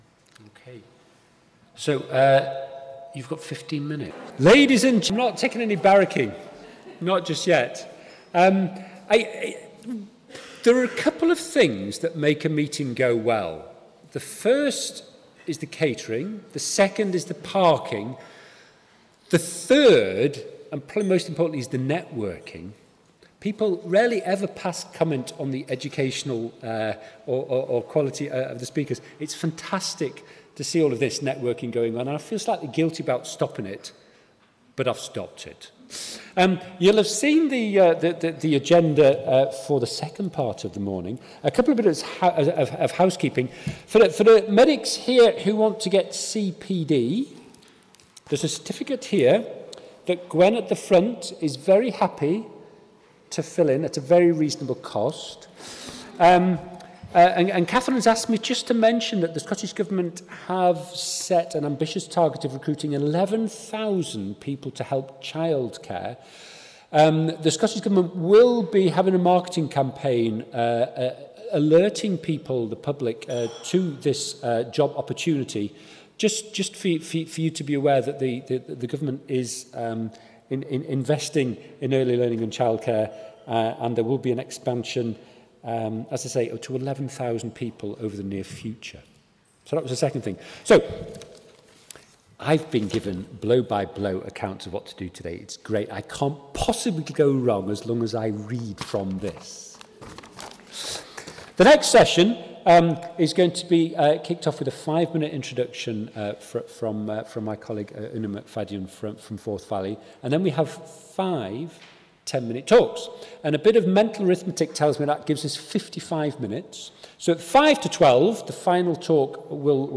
2.-AM-panel.-Challenges-in-establishing-a-level-playing-field.mp3